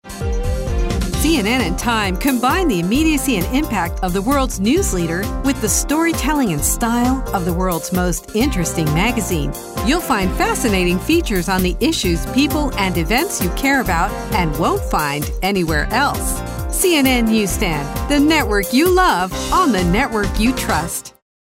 a smooth and resonant voice
News promos (friendly, assertive)